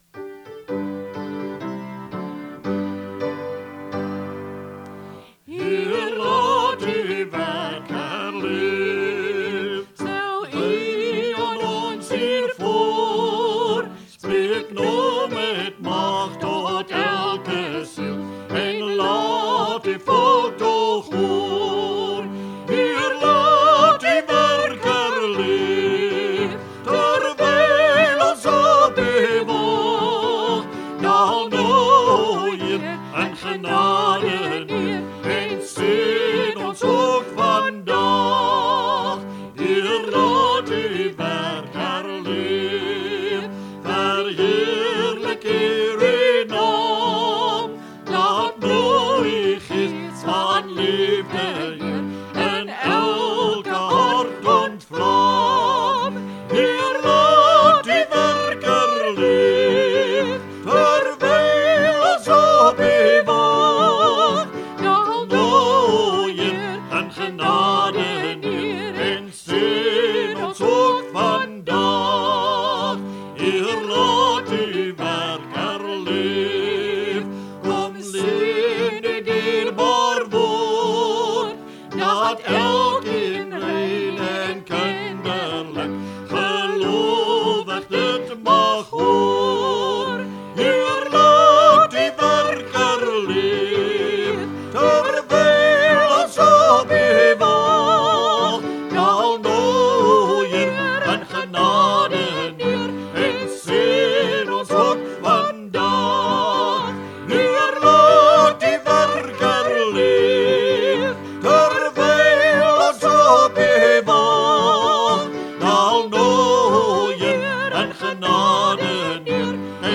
Liedere - Begeleiding en samesang
Dit is geensins professionele opnames nie.